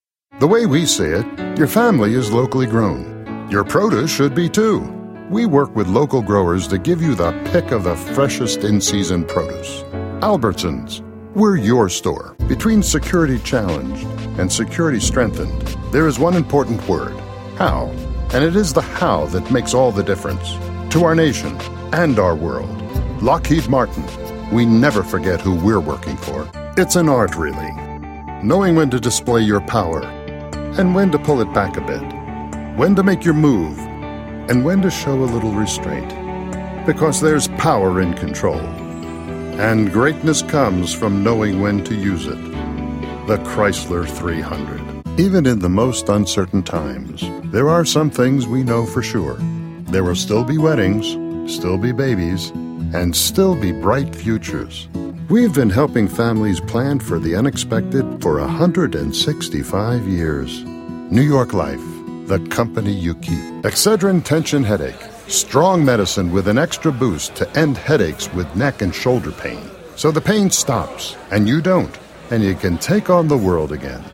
Authoritative, professional, sincere, conversational, confident,charming,energetic courteous
mid-atlantic
Sprechprobe: Werbung (Muttersprache):